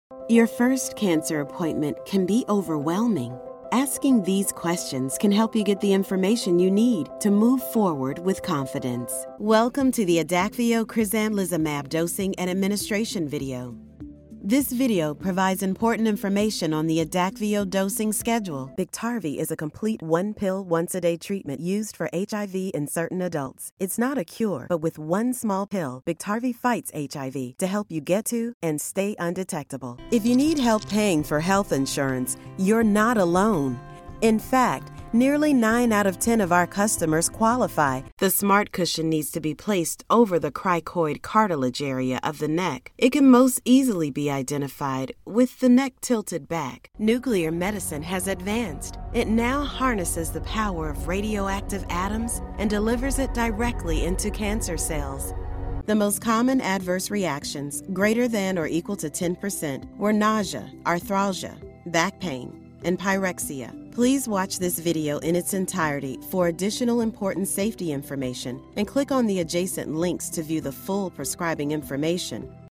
Has Own Studio
medical